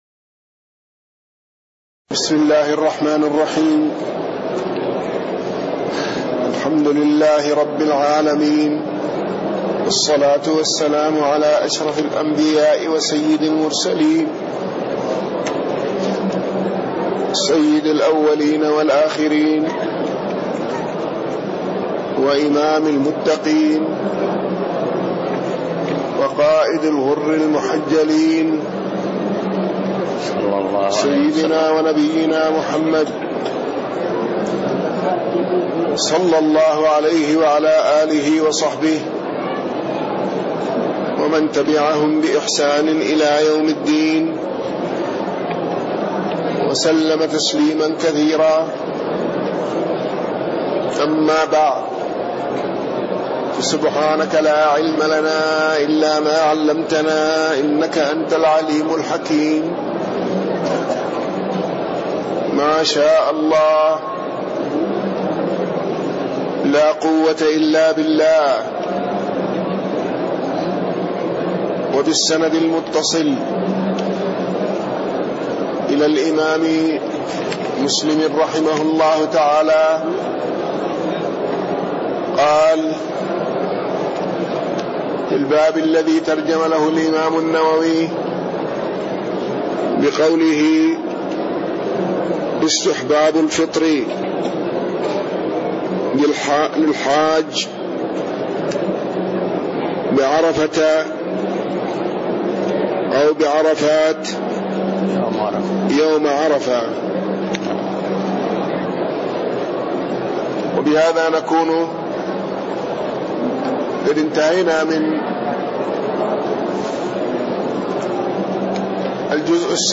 تاريخ النشر ٢٣ رجب ١٤٣٣ هـ المكان: المسجد النبوي الشيخ